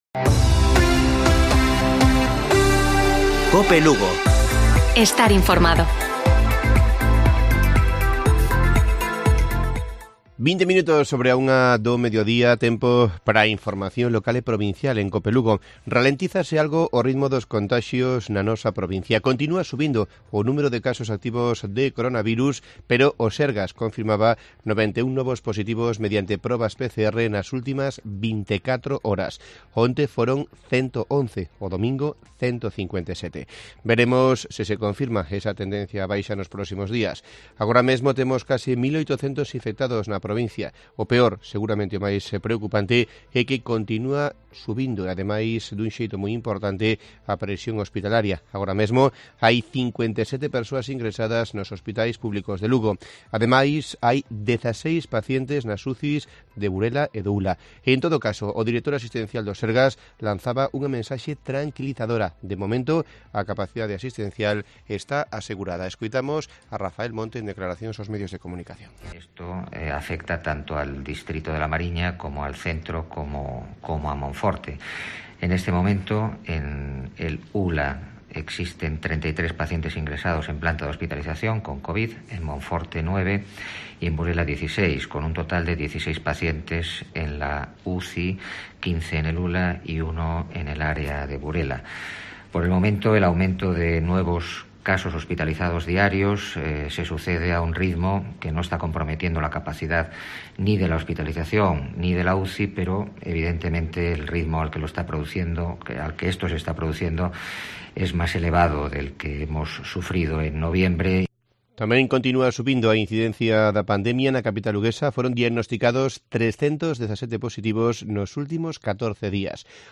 Informativo Provincial de Cope Lugo. 26 de enero. 13:20 horas